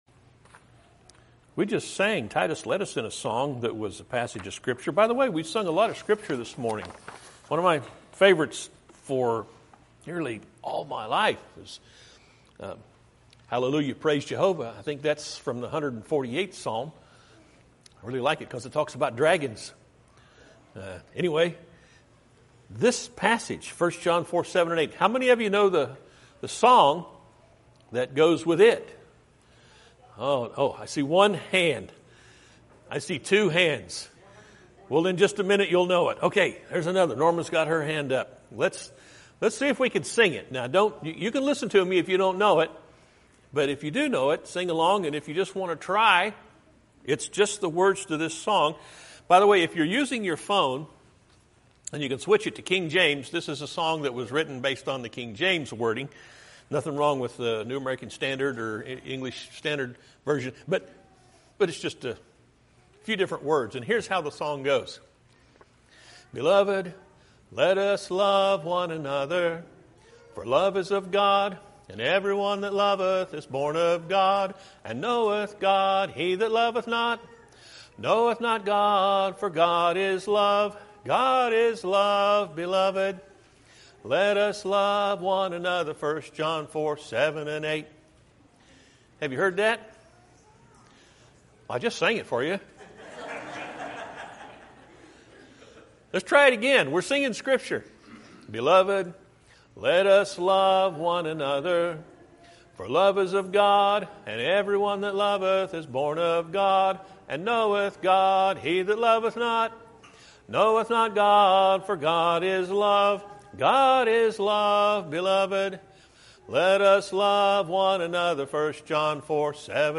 Podcasts Videos Series Sermons God is love, but…